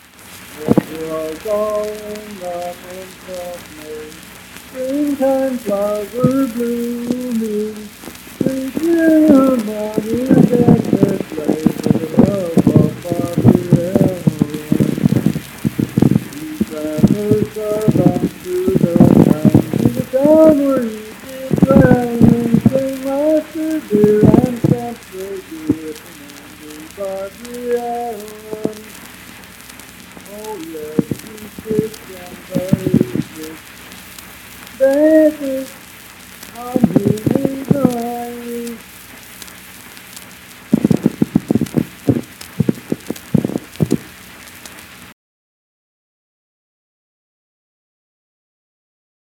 Unaccompanied vocal music performance
Verse-refrain 3(4).
Voice (sung)
Randolph County (W. Va.)